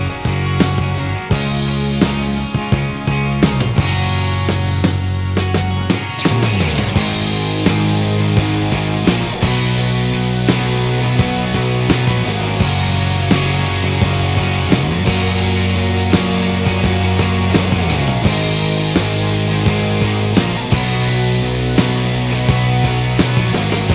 Minus Guitars Rock 3:26 Buy £1.50